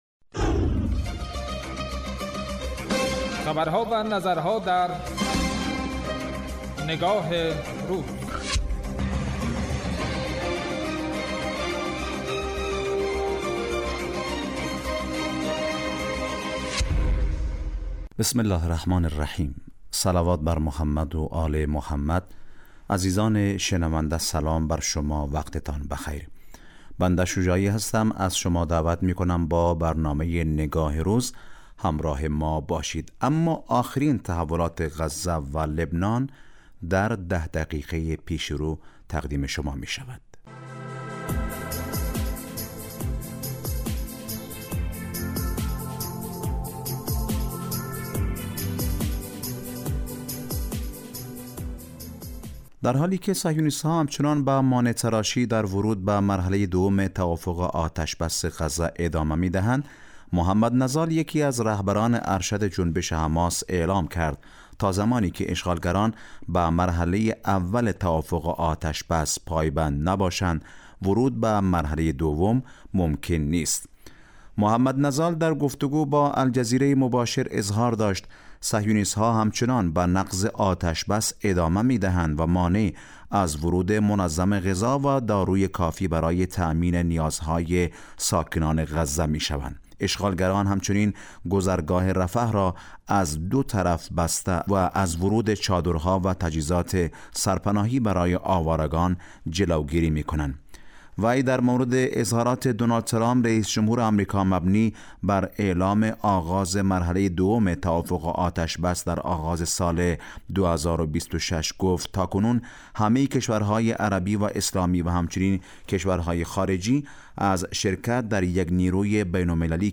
برنامه تحلیلی نگاه روز